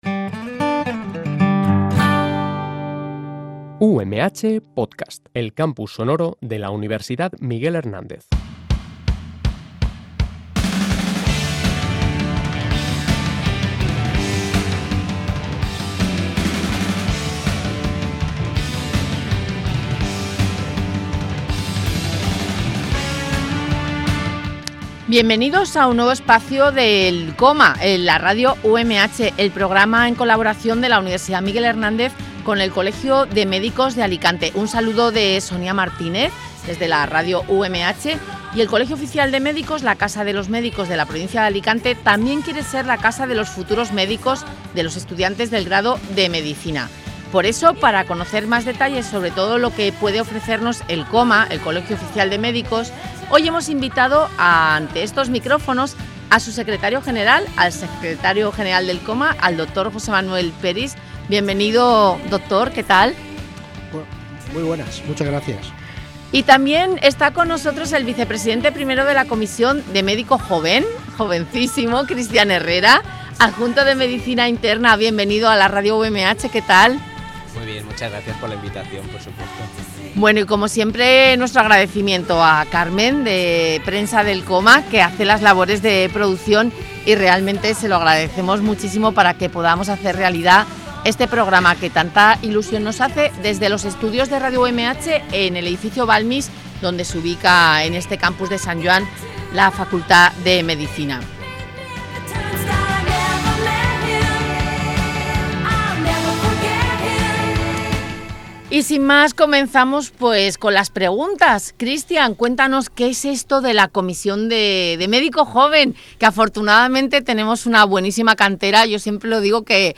El Colegio Oficial de Médicos de Alicante (COMA) ha participado en una nueva entrega del programa “El COMA en la UMH”, emitido desde los estudios de Radio UMH para toda la comunidad universitaria, con un claro objetivo: acercarse a los estudiantes del Grado de Medicina y poner en valor todo lo que el Colegio puede ofrecerles desde su etapa universitaria.